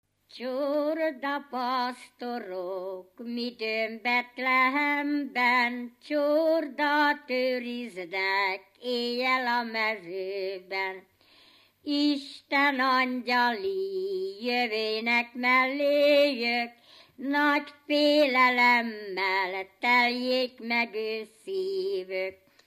Felföld - Bars vm. - Barslédec
Stílus: 7. Régies kisambitusú dallamok
Szótagszám: 5.6.5.6
Kadencia: 1 (3) 1 1